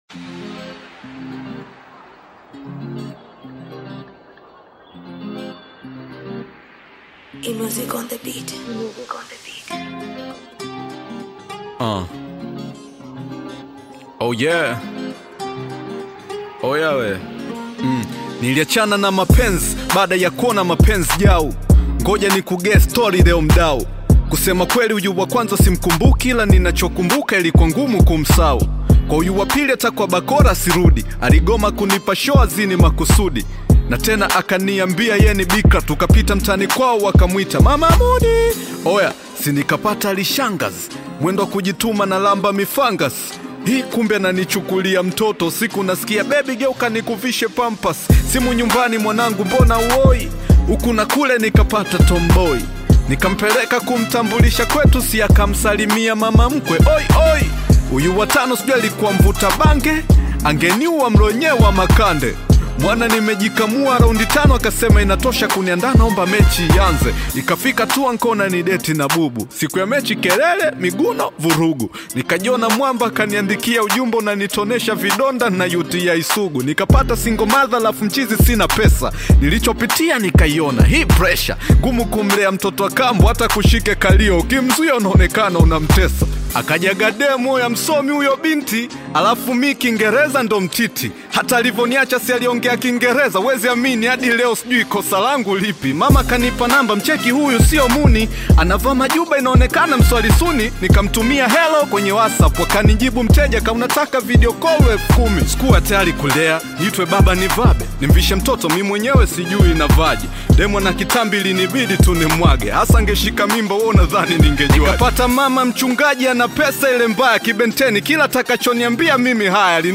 Bongo Flava music track
Tanzanian Bongo Flava artist, singer, and songwriter
Bongo Flava song